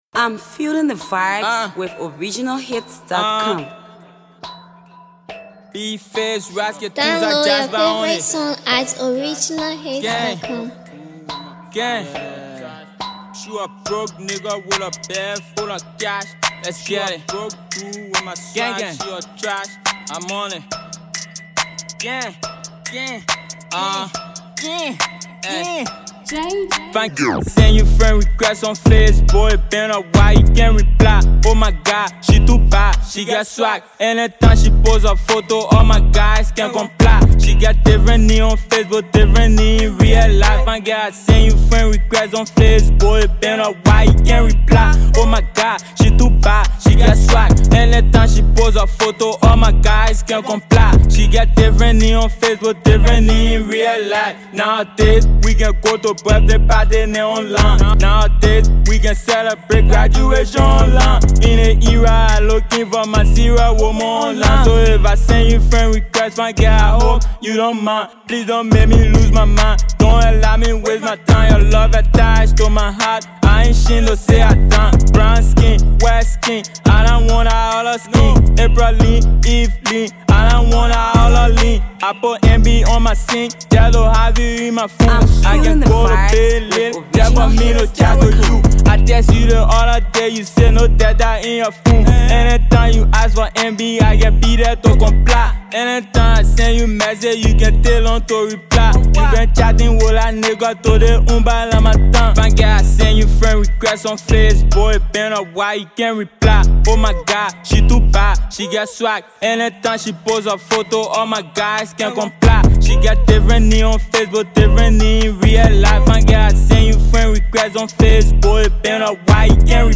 Hot freestyle from the versatile upcoming artist